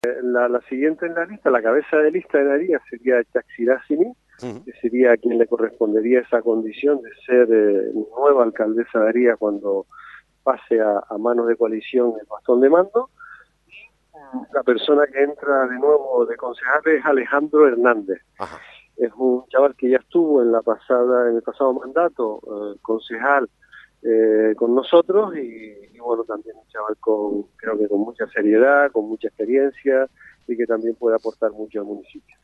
Así lo ha confirmado en el informativo 'Mediodía Lanzarote' de Crónicas Radio-COPE Lanzarote Marci Acuña, el ex alcalde de Haría y actual teniente de alcalde, que ha renunciado este martes a su acta como concejal de CC en Haría.